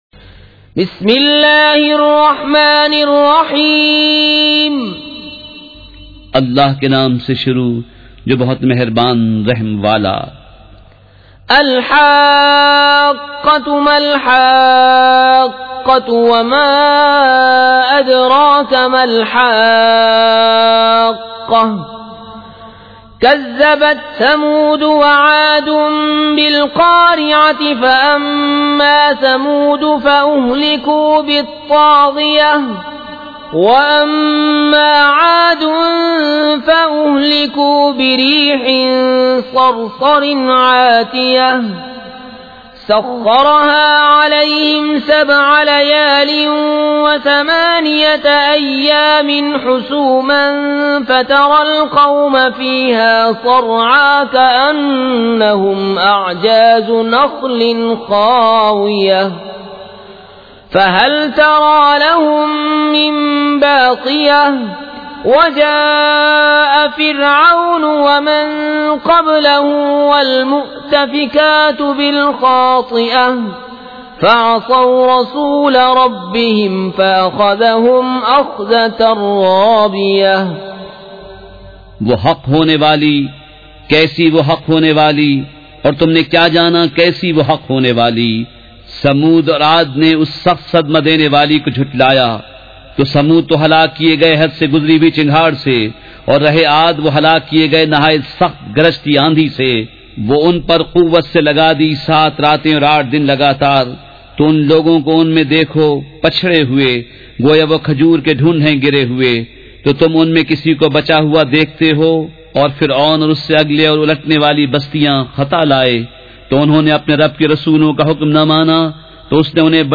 سورۃ الحاقّۃ مع ترجمہ کنزالایمان ZiaeTaiba Audio میڈیا کی معلومات نام سورۃ الحاقّۃ مع ترجمہ کنزالایمان موضوع تلاوت آواز دیگر زبان عربی کل نتائج 1848 قسم آڈیو ڈاؤن لوڈ MP 3 ڈاؤن لوڈ MP 4 متعلقہ تجویزوآراء